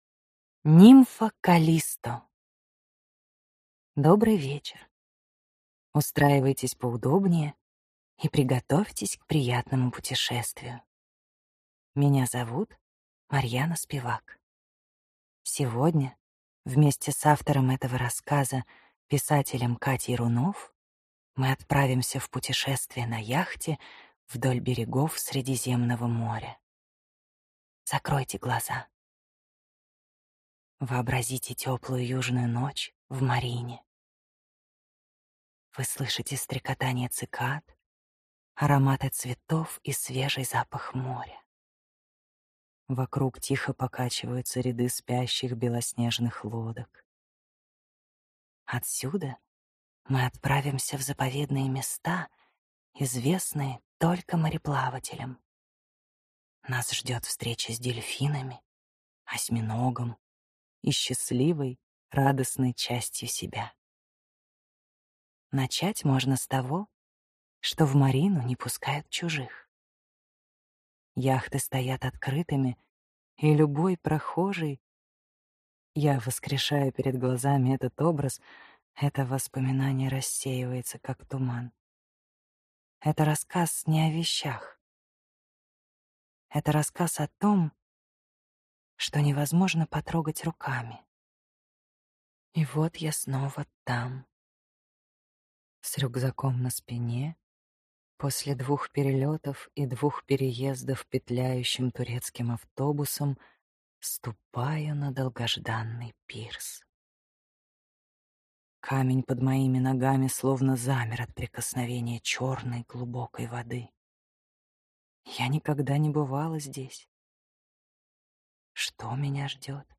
Аудиокнига Нимфа «Каллисто» | Библиотека аудиокниг
Прослушать и бесплатно скачать фрагмент аудиокниги